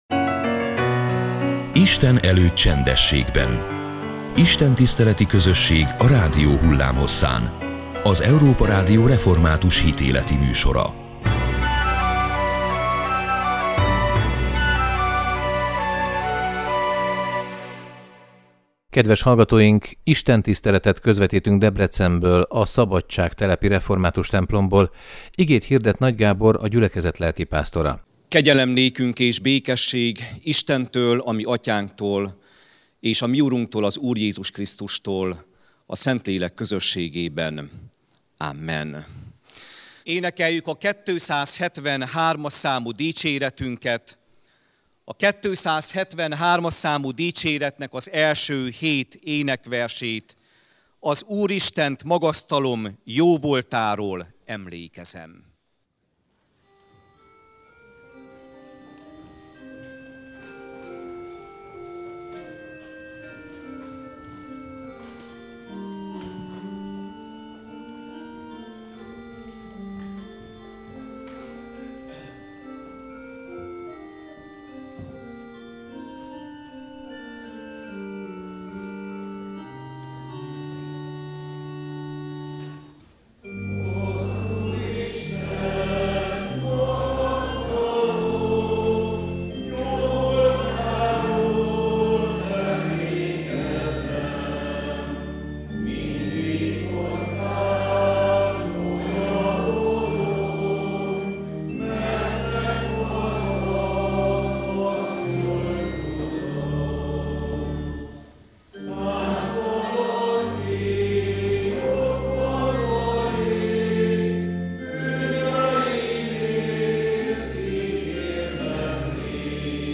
Istentiszteleti közösség a rádió hullámhosszán. Az Európa Rádió hitéleti műsora minden vasárnap és a református egyház ünnepnapjain.